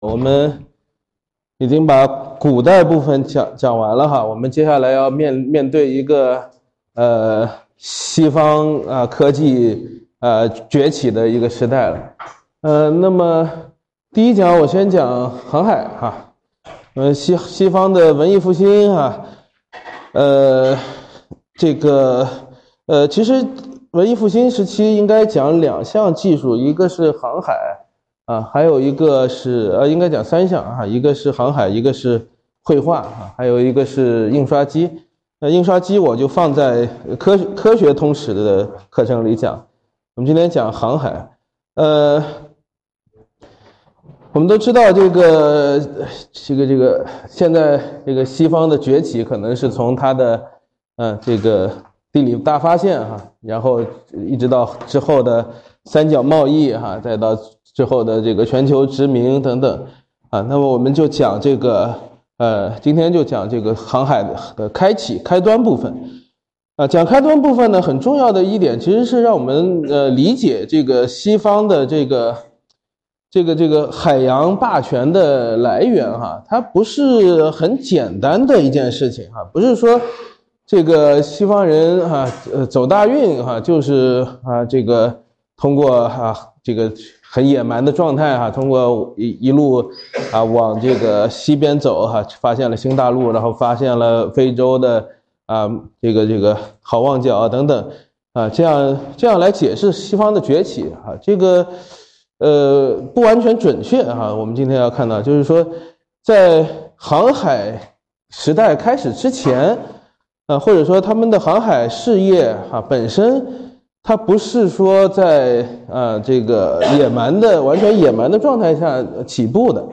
此处分享课程全程PPT和录音资料。